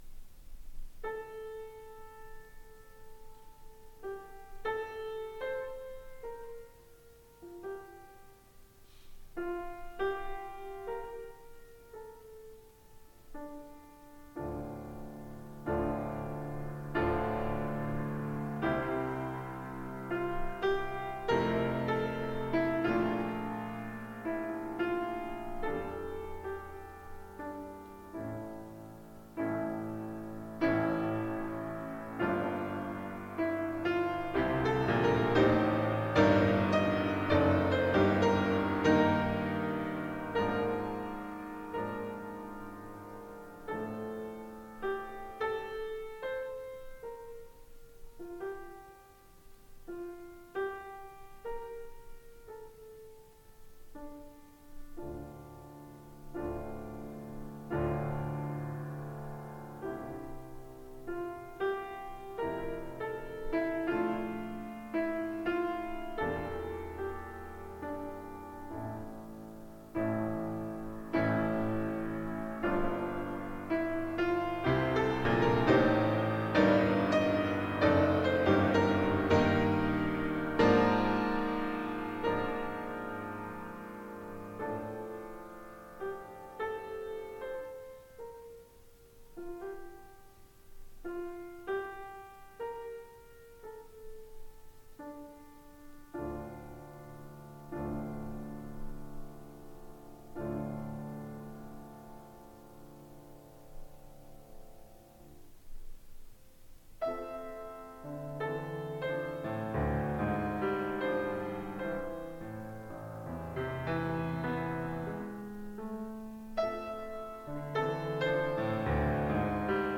Recital - April 10, 1994